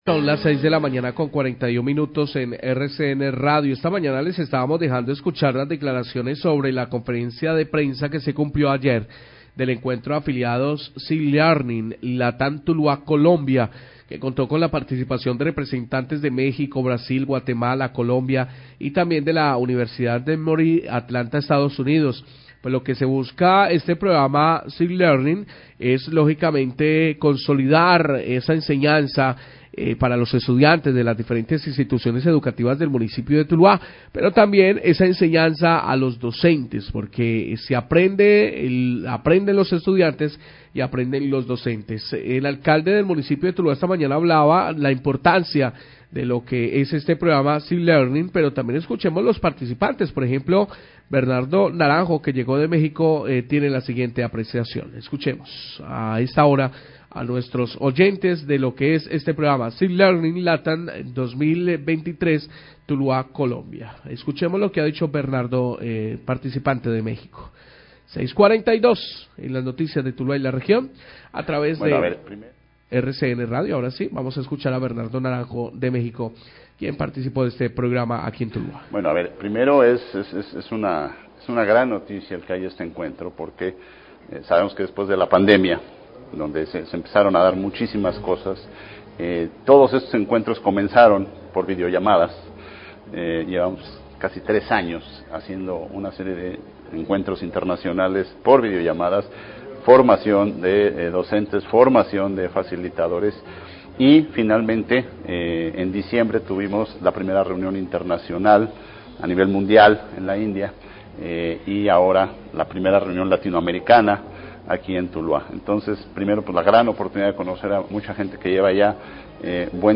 Participantes y Alcalde de Tuluá hablan de la importancia del encuentro del Programa See Learning
Radio